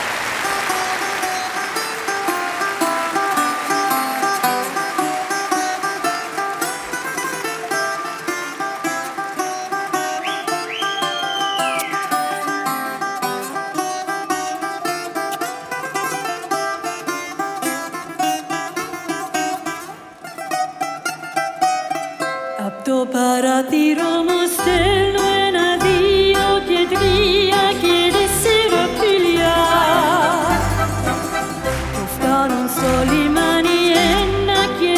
Струнные и рояль
Classical Crossover Classical
Жанр: Классика